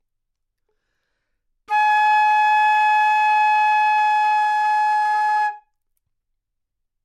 长笛单音 " 长笛 Gsharp5
描述：在巴塞罗那Universitat Pompeu Fabra音乐技术集团的goodsounds.org项目的背景下录制。
标签： 好声音 单注 多样本 Gsharp5 纽曼-U87 长笛
声道立体声